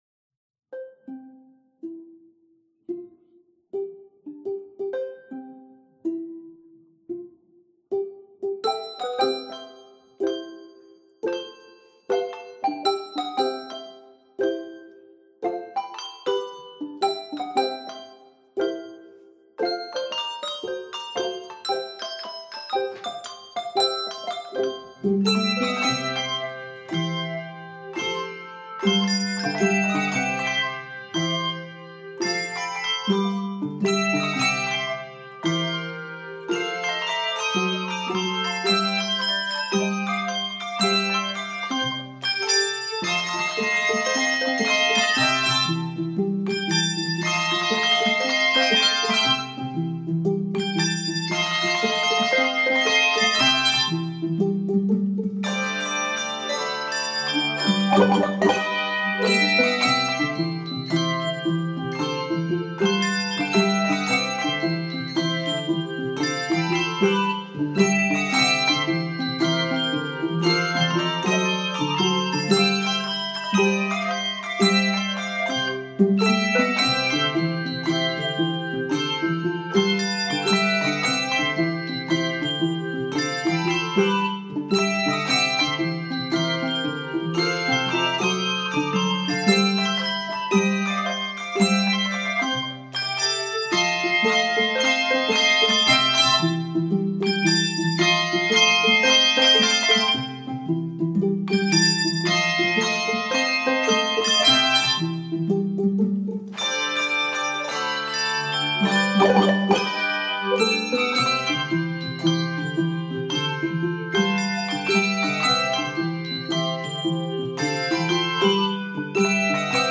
Full of fun techniques, this energetic original tune
Key of C Major.